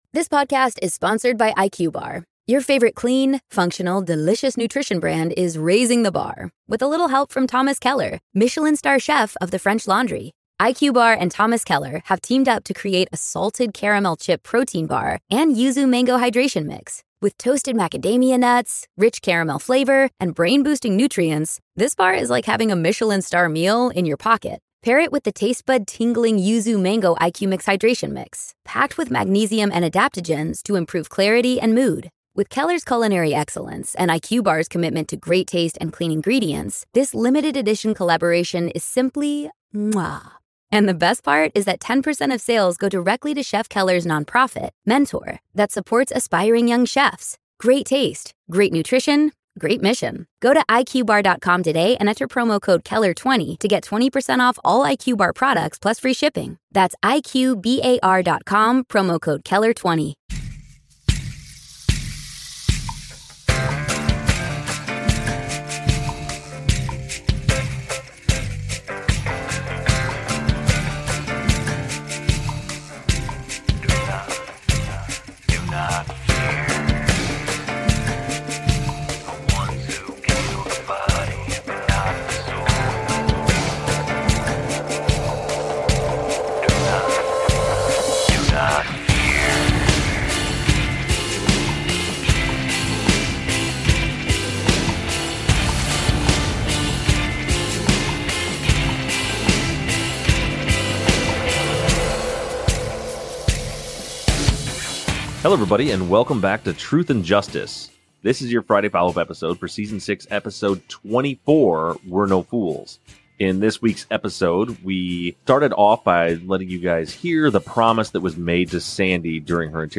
answers listener questions form social media and voicemails.